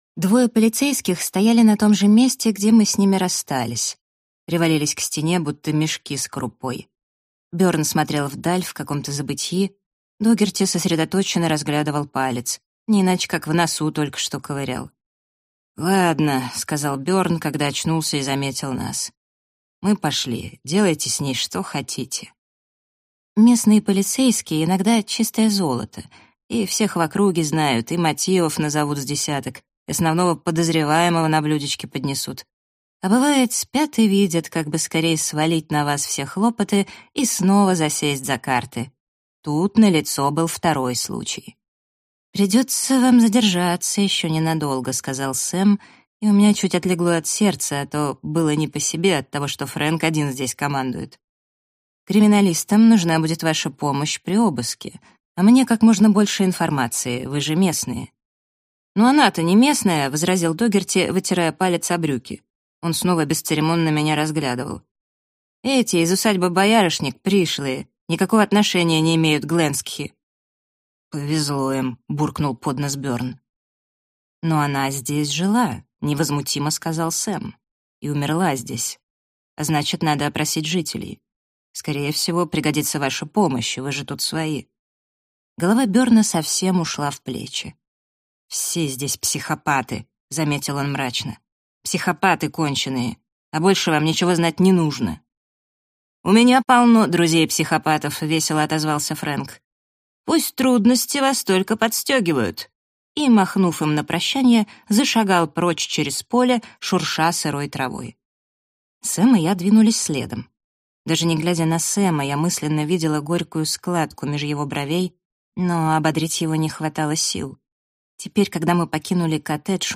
Аудиокнига Сходство | Библиотека аудиокниг